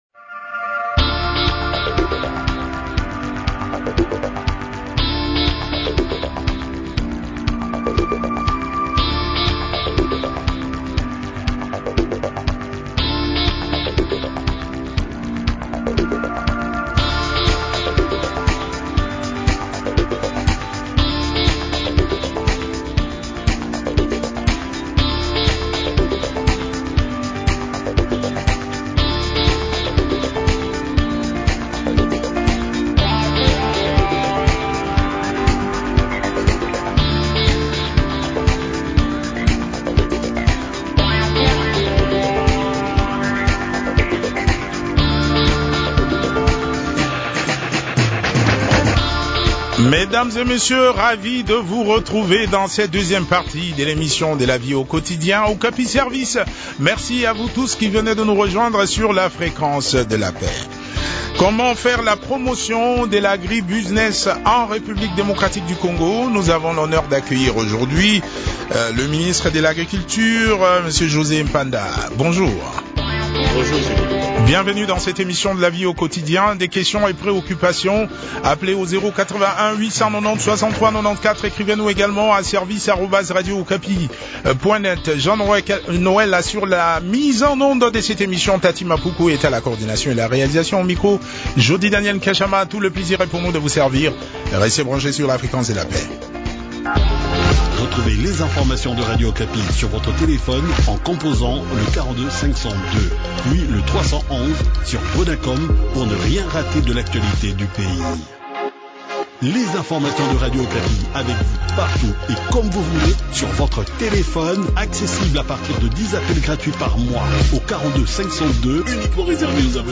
discute de ce sujet avec Me José Panda, ministre de l’Agriculture.